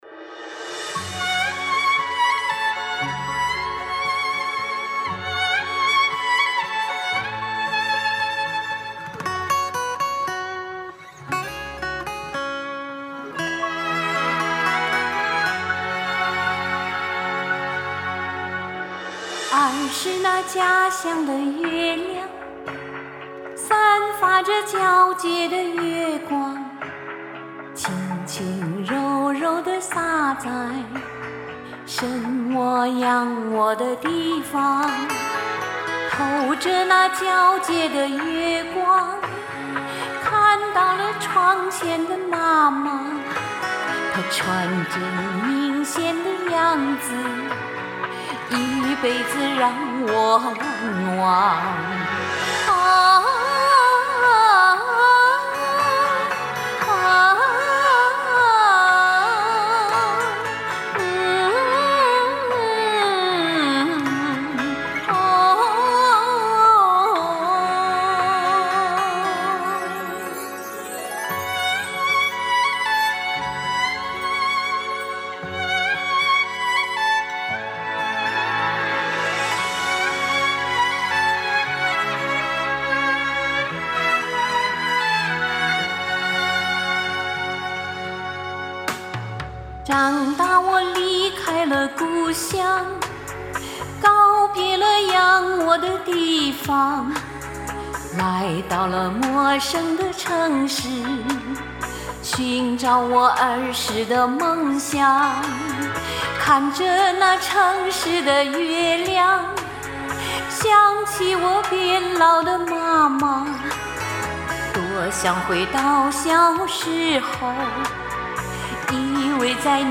俏丽甜润婉转的声音， 饱蘸深情的演绎！
真挚甜美的歌声打动人心，好棒啊！
甜美轻柔的叙述，真好听
还是那么清脆柔美
纯净、高扬的声线，优美而略带伤感的旋律。